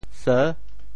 “俟”字用潮州话怎么说？